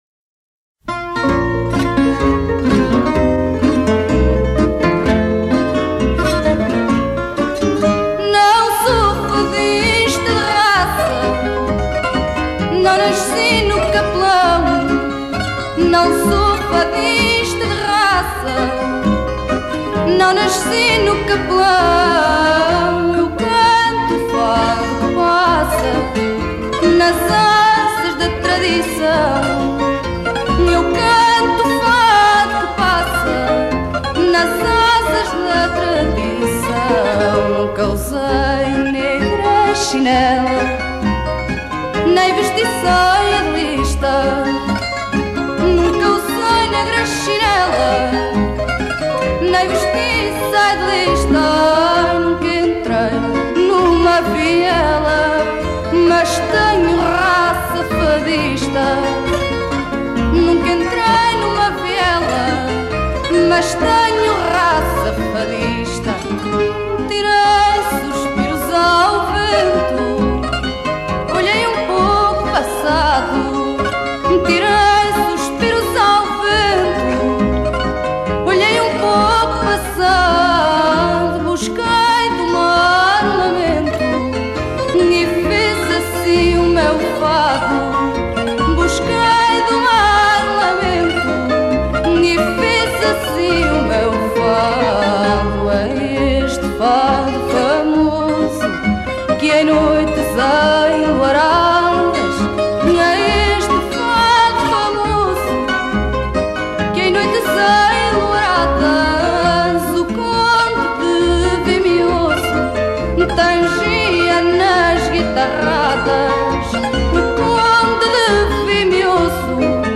Genre: Fado, Folk